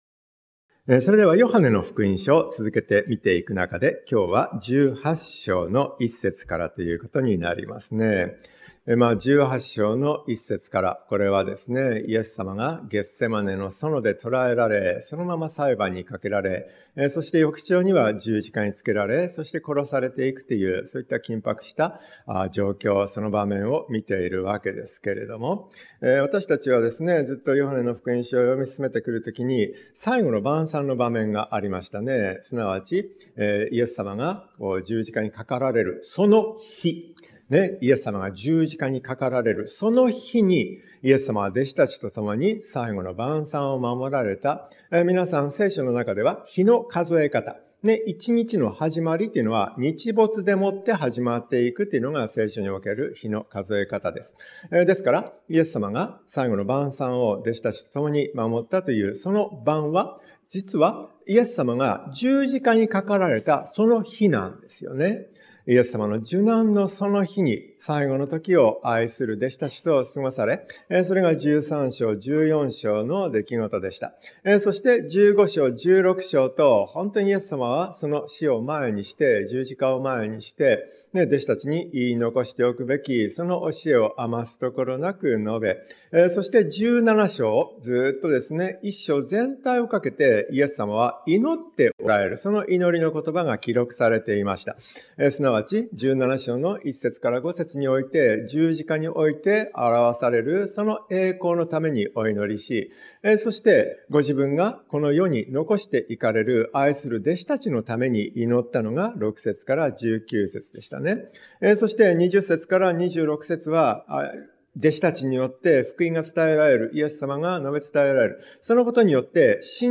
園で捕らえられるイエス 説教者